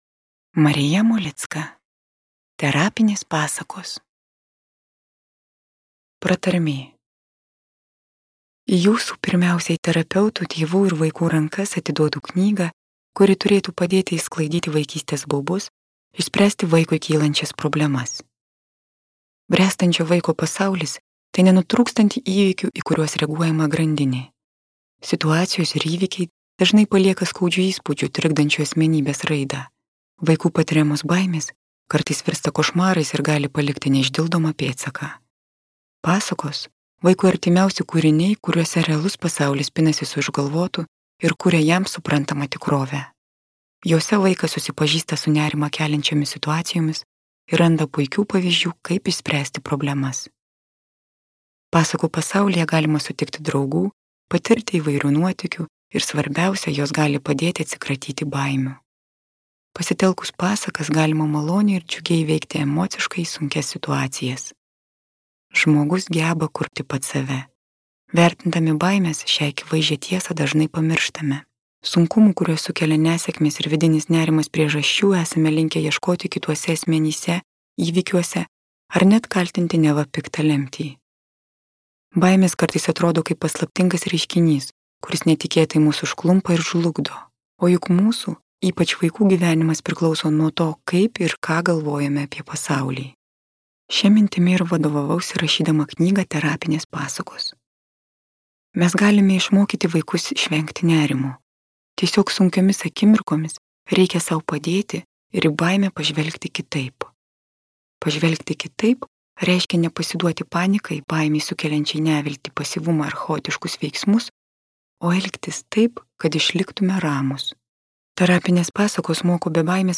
M. Molickos audioknyga